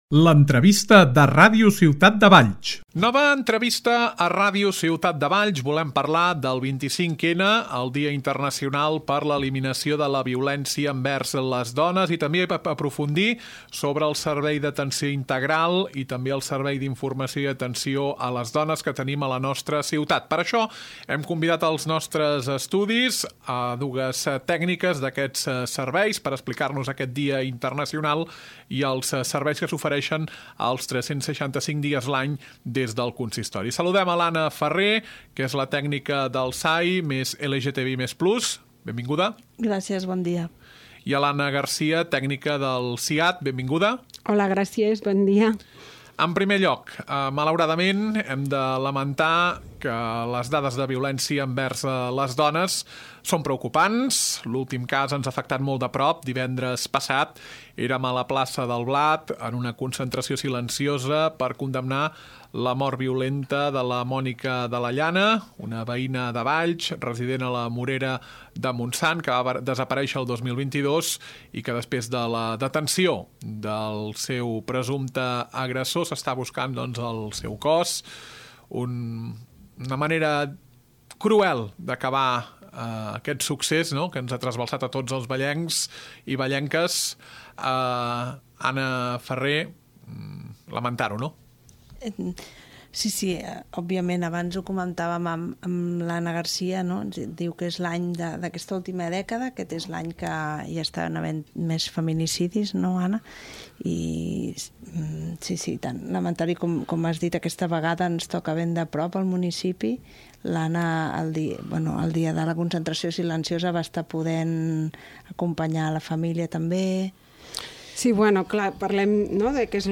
Nova entrevista a Ràdio Ciutat de Valls. Abordem les activitats que s’organitzen a la nostra ciutat en el marc del 25N, Dia Internacional per a l’eliminació de la violència envers les dones.